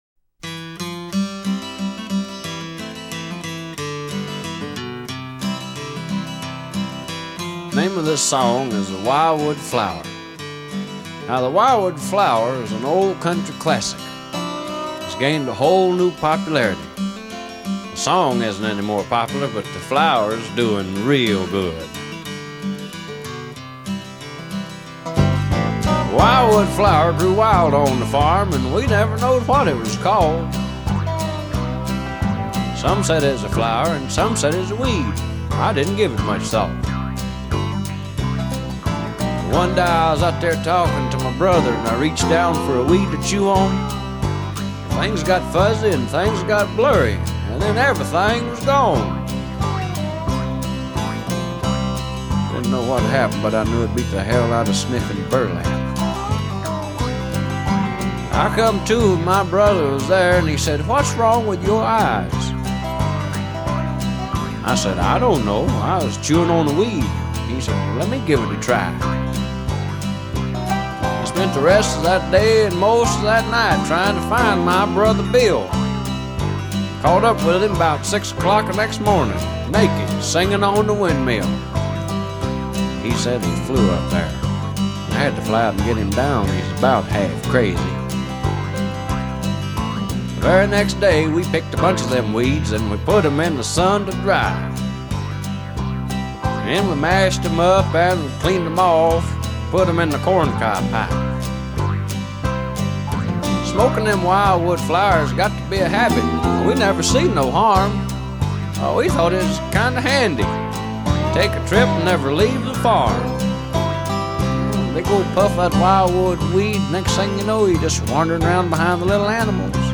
Tags: Funny Comedy Rock Music Cool adult